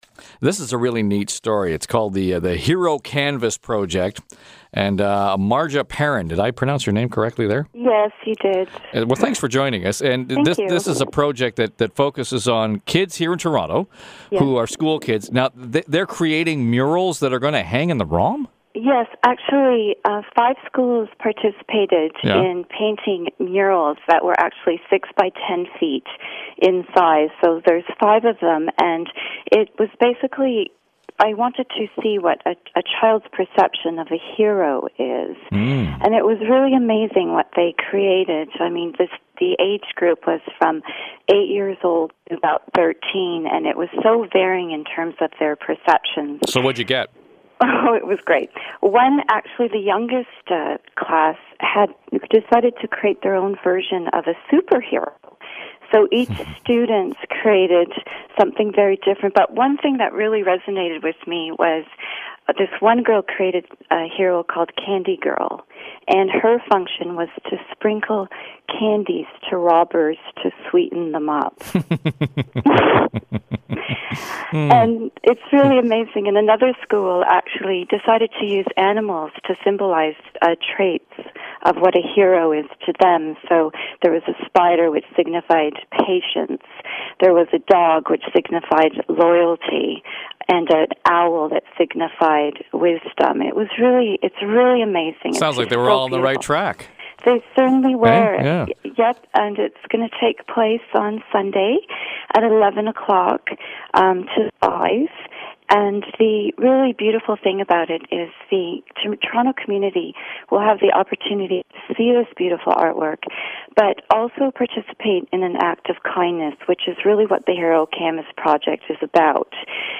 01 Hero Canvas Project Pre-event_CFRB Radio Interview.m4a